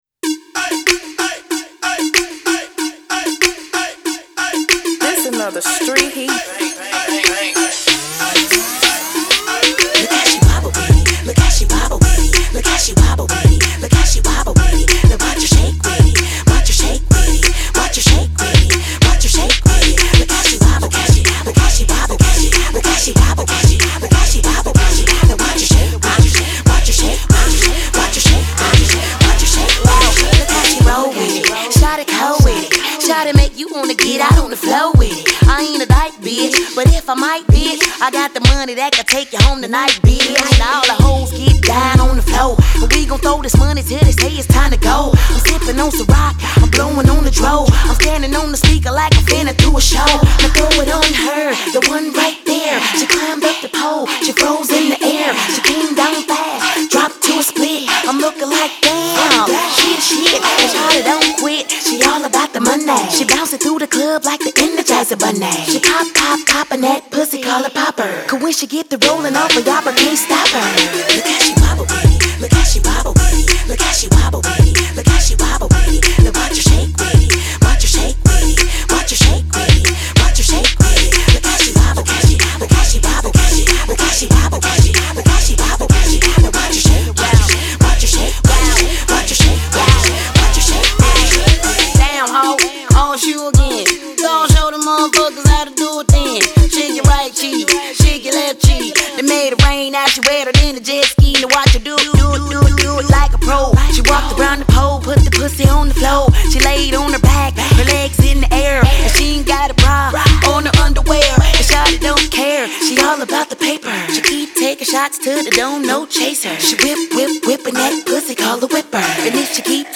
catchy single